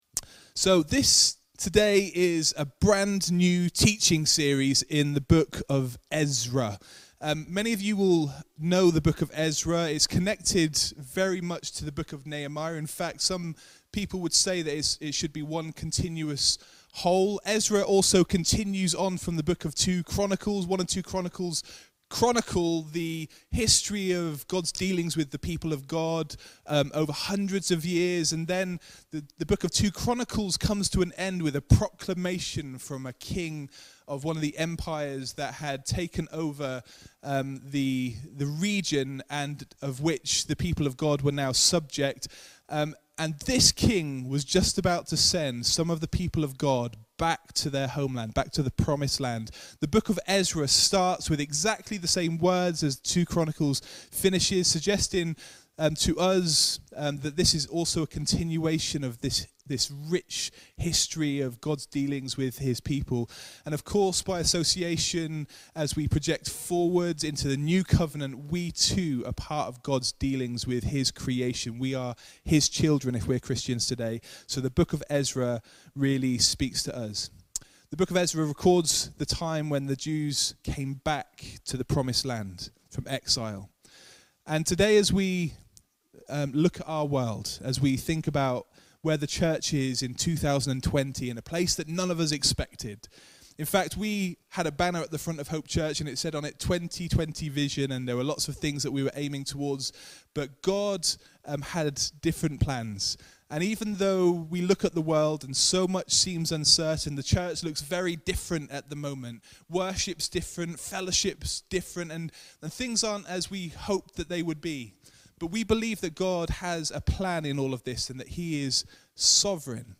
The-Sovereignty-of-God-Hope-Church-Live-128-kbps.mp3